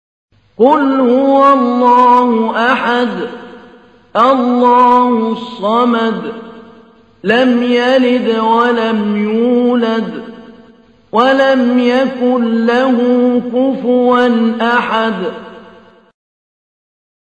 تحميل : 112. سورة الإخلاص / القارئ محمود علي البنا / القرآن الكريم / موقع يا حسين